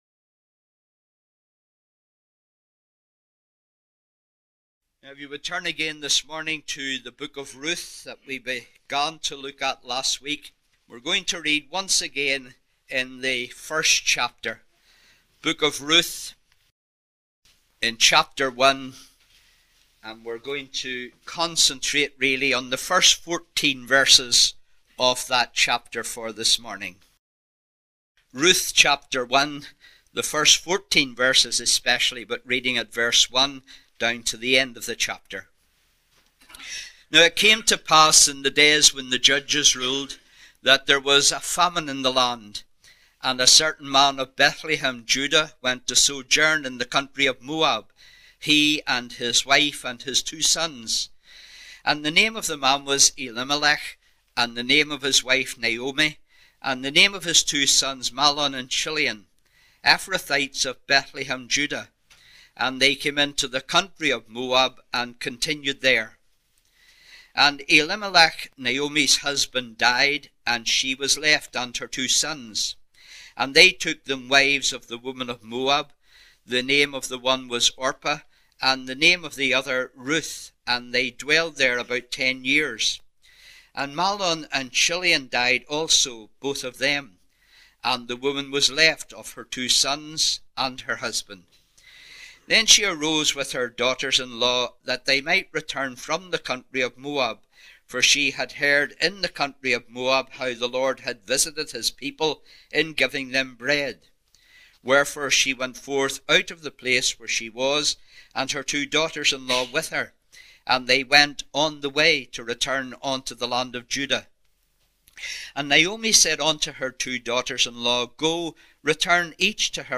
10 sermons